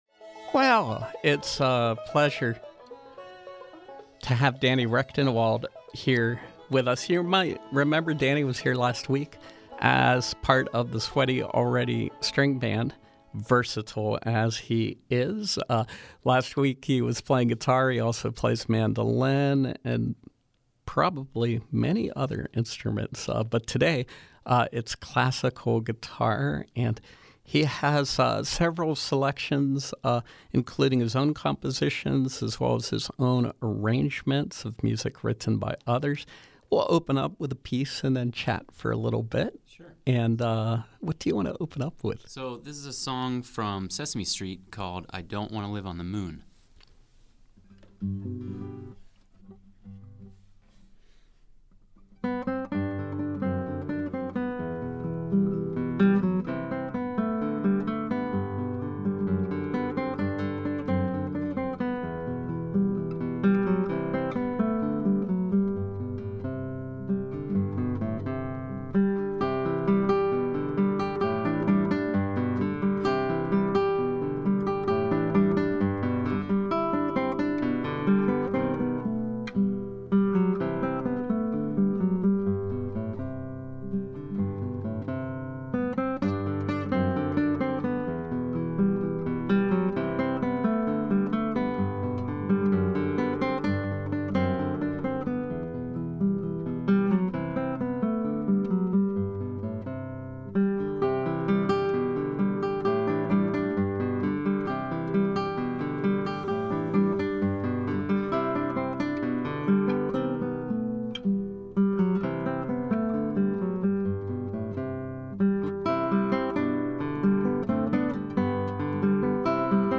Live Music
Classical guitar